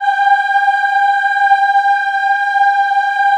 G4 WOM AH -R.wav